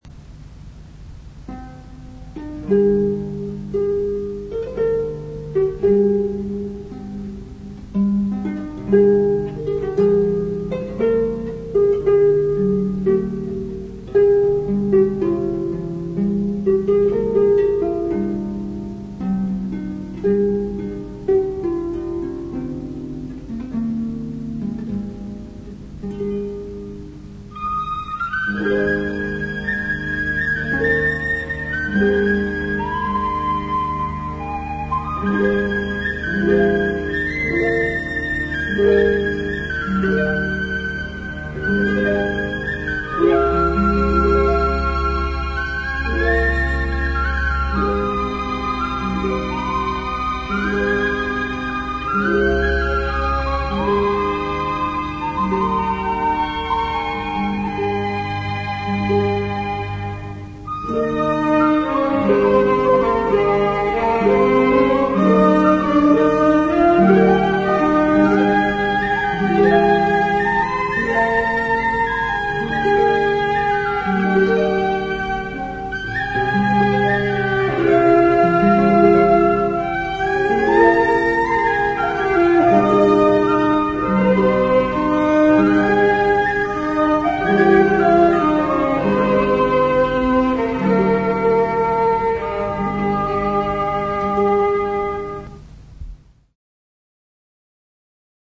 with a taste of genuine Irish Folk music
Instruments peculiar to Ireland such as the Harp, the Piano Accordion and the Tin Whistle give this melody a special tone and shade: